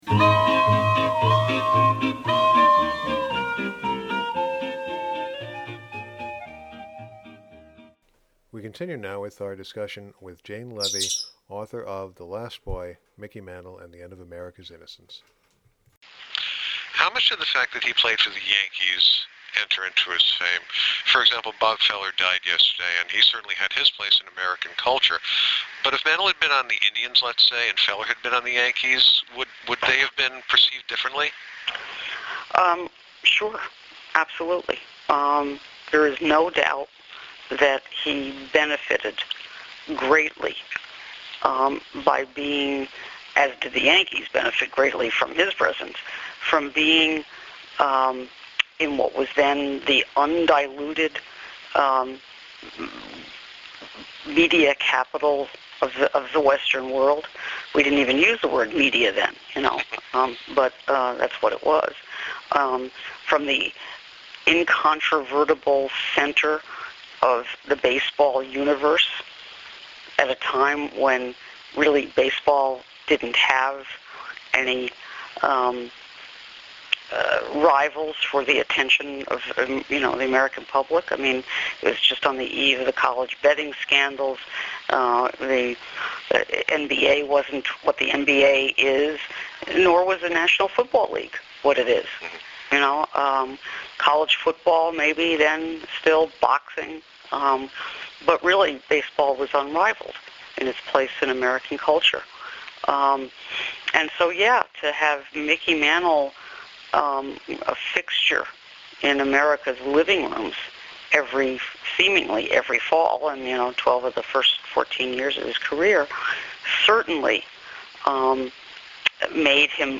Jane Leavy played to a full house in October at the Yogi Berra Museum and learning Center in Little Falls.